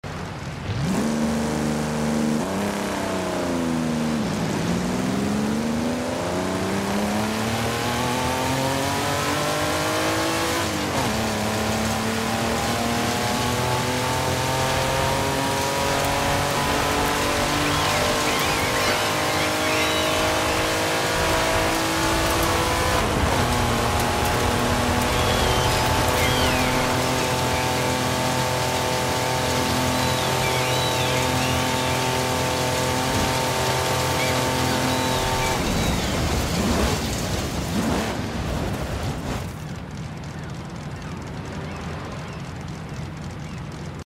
1986 Lamborghini LM 002 Off Road sound effects free download